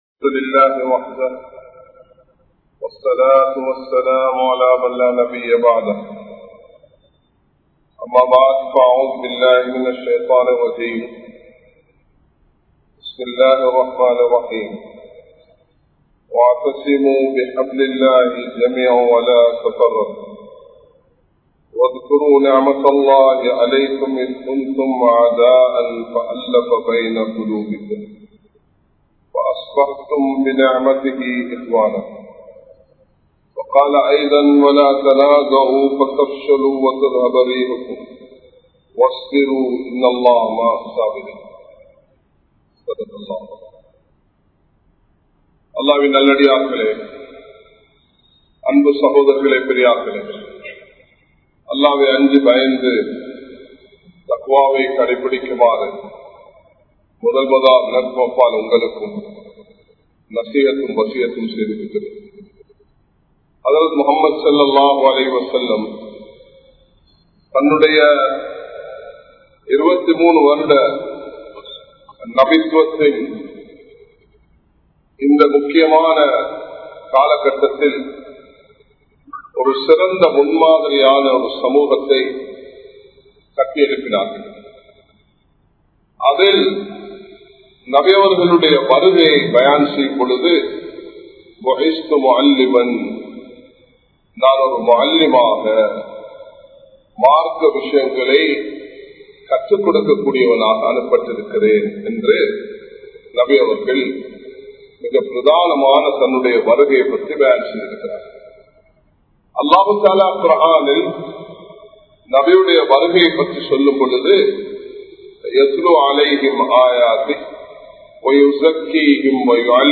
Nabimaarhalin Arivu (நபிமார்களின் அறிவு) | Audio Bayans | All Ceylon Muslim Youth Community | Addalaichenai
Kollupitty Jumua Masjith